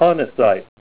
Help on Name Pronunciation: Name Pronunciation: Honessite + Pronunciation
Say HONESSITE